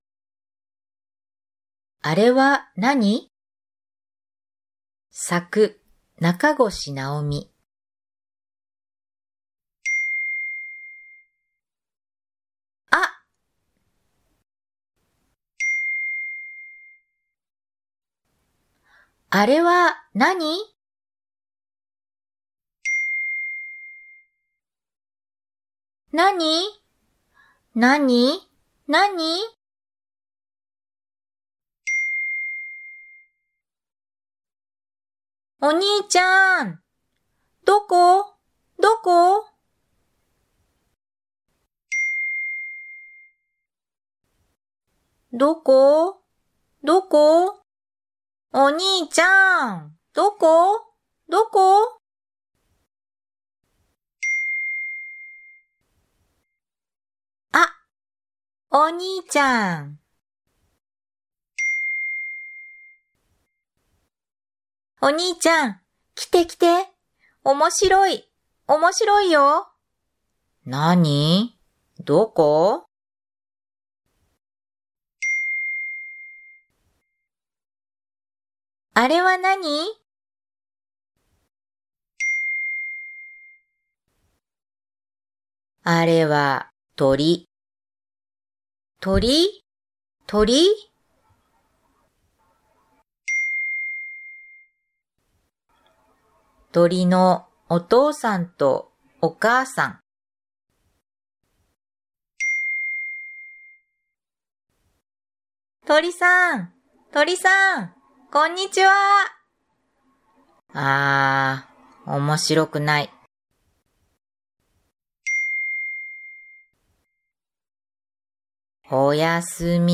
朗読音声付き